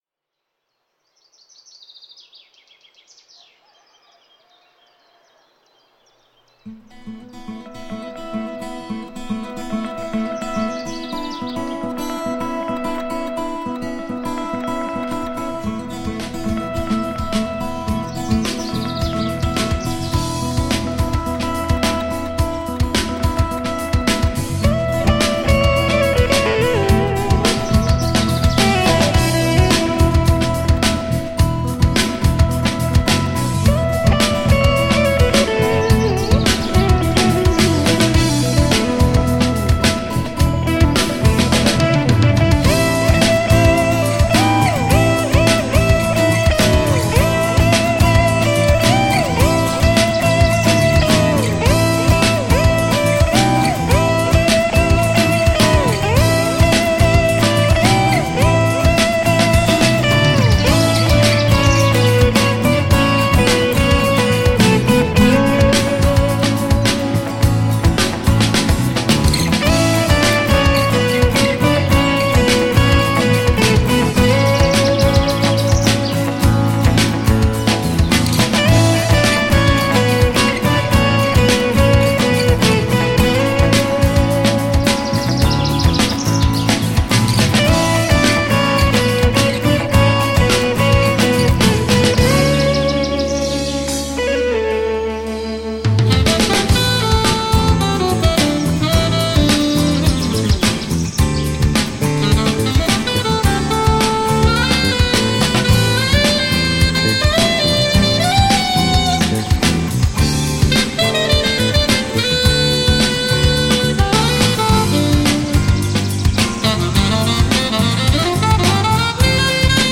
Žánr: Rock
kytarových fantazií z pomezí rocku a jazzu